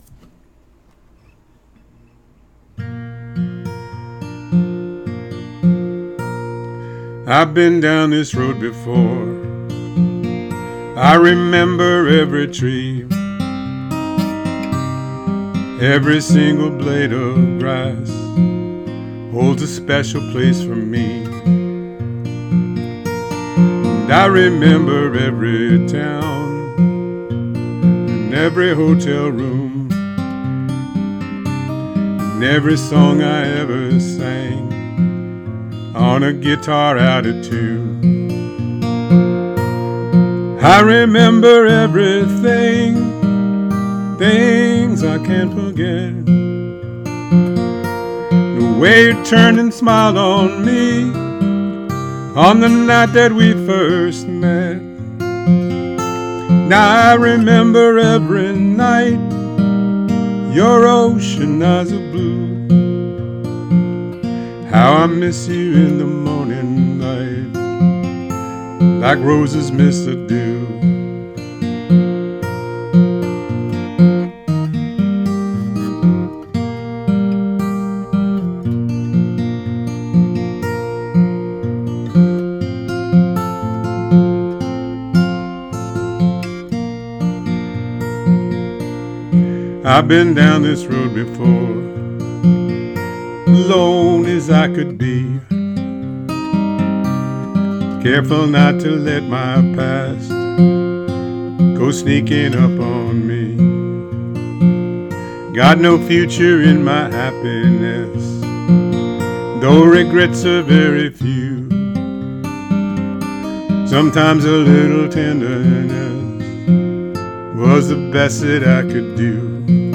Here's my cover.